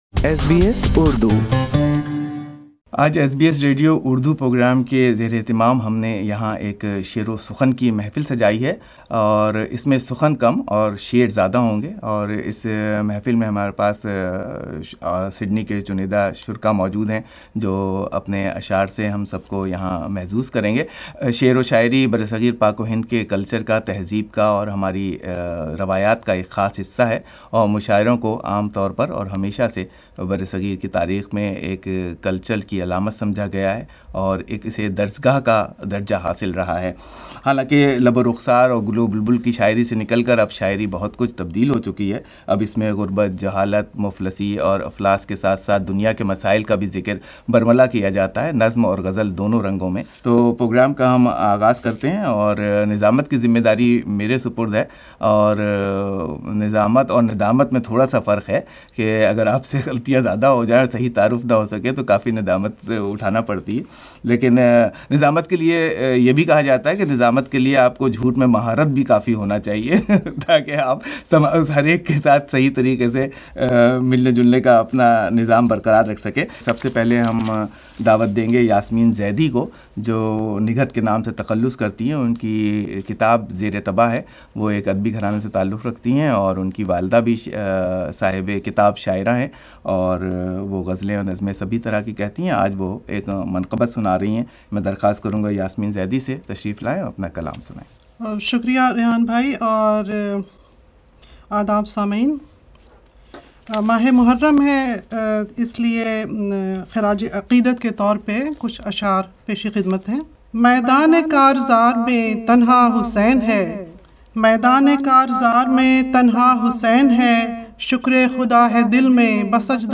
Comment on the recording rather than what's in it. Poetry gatherings and literary congressional sessions are part of Subcontinent heritage and culture for centuries. It was a bright and shiny week-end autumn morning when SBS multi-track studio echoed with beautiful traditional melidious poetry.